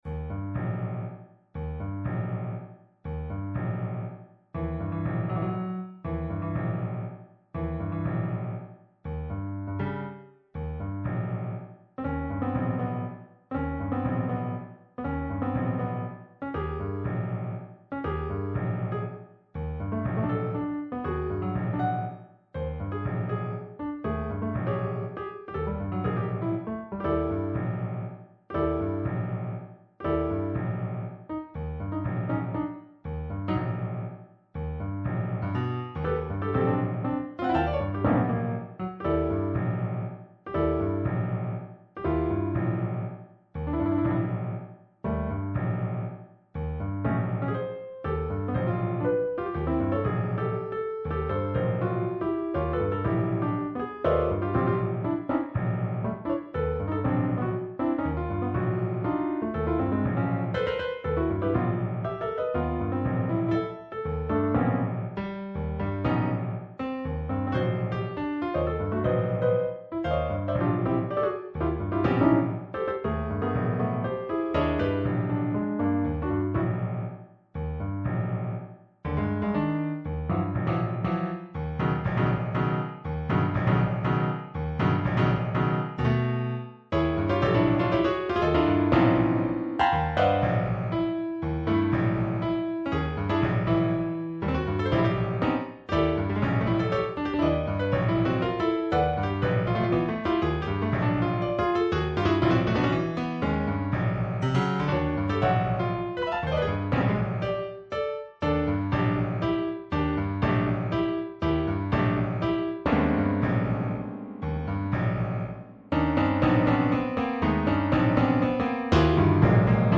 Instrument: piano solo.
I consider it my top composition, but it demands a high degree of viruosity and the concluding passage is essential.